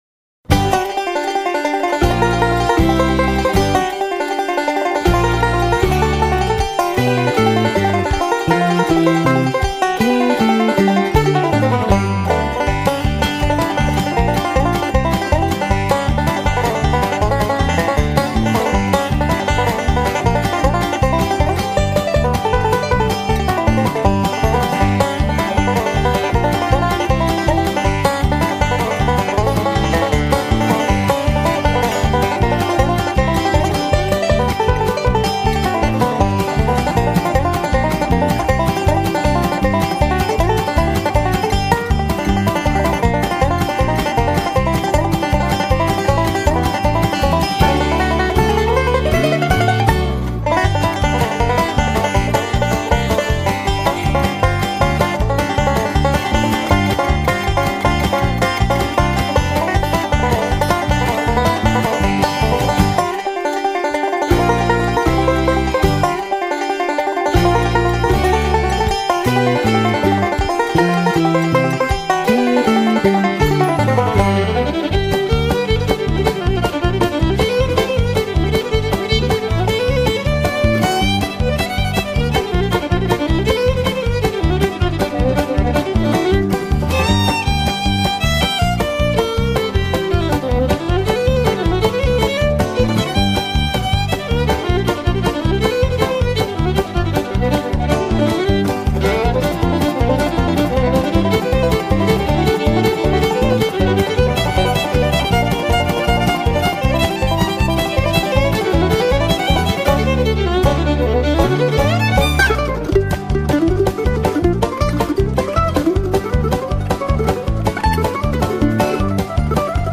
Красивая музыка на банджо